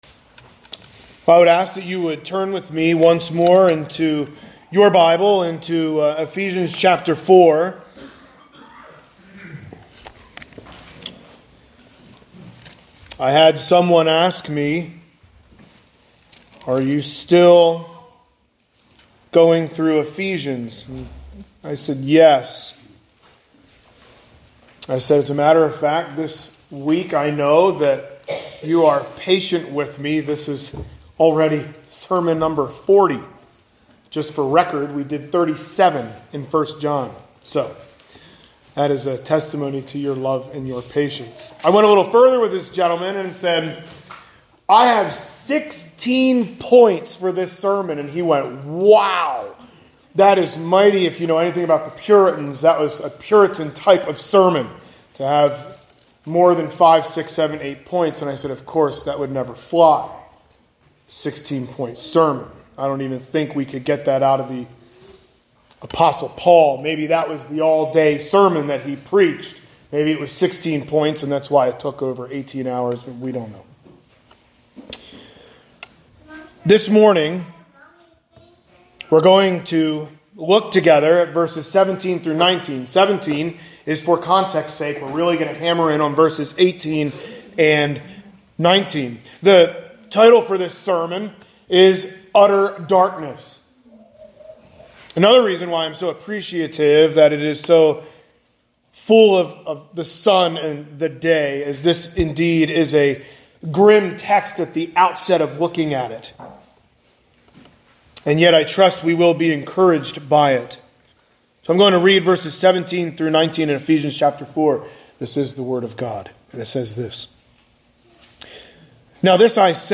Sunday Morning Sermons | Zionsville Bible Fellowship Church